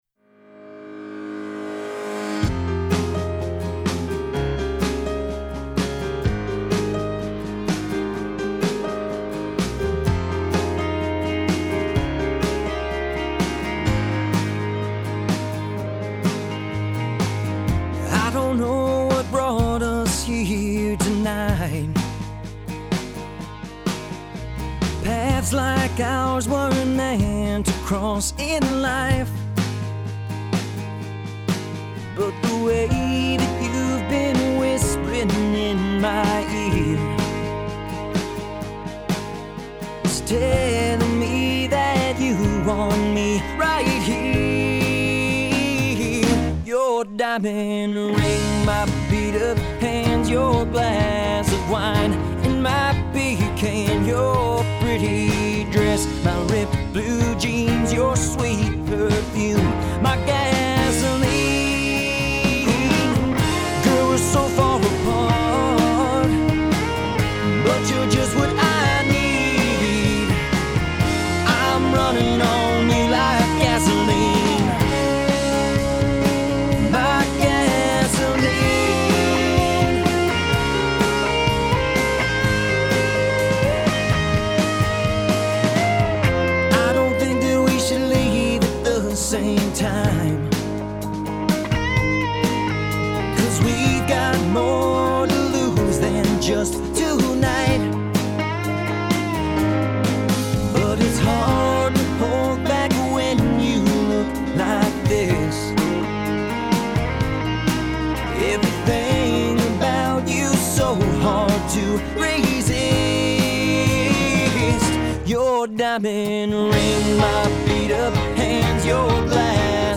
Lady Antebellum style
Singer